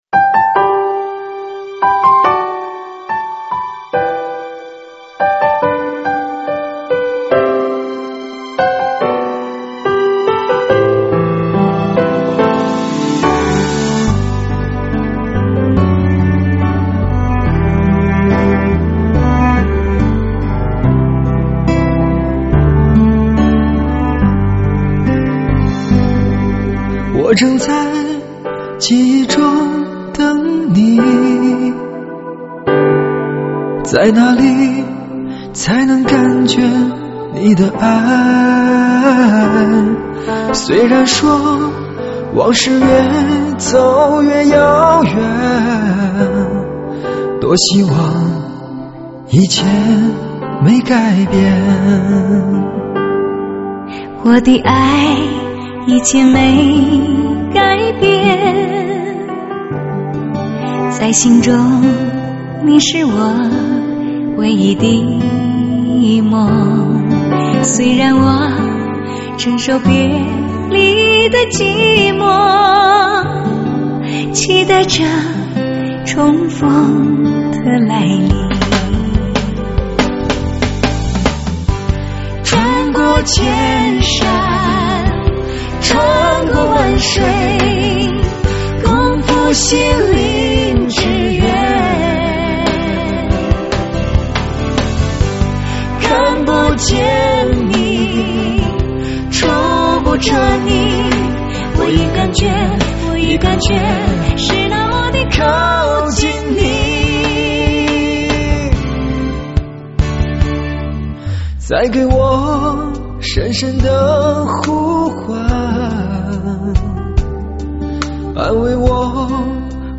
深情动人经典情缘金曲 HiFi发烧3D时代降临